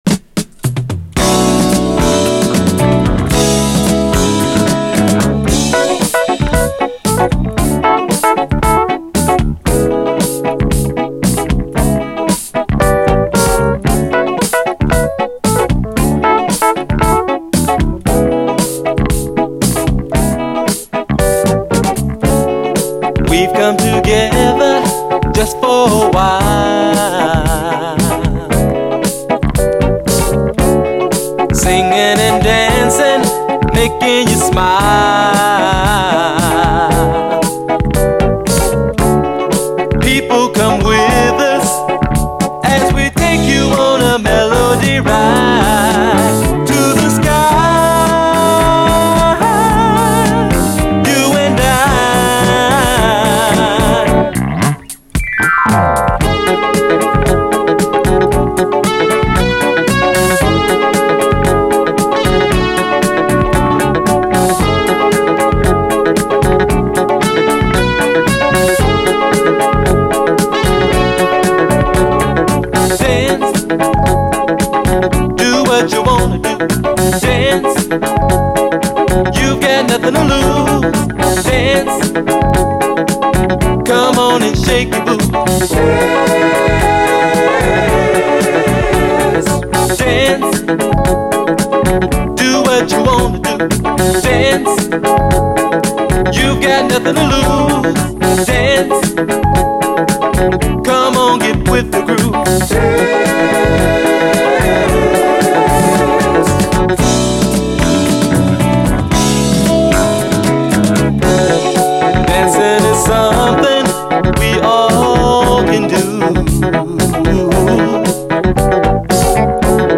SOUL, 70's～ SOUL, DISCO
滑らかなエレピが演出する、爽快モダン・ソウル！